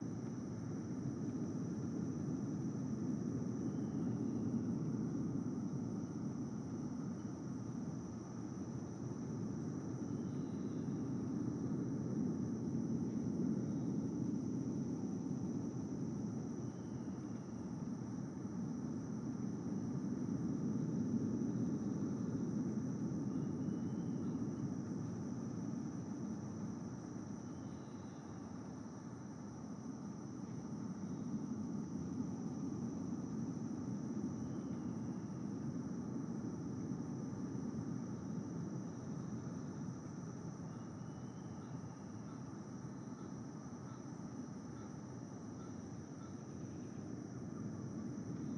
base-wind-gleba-day.ogg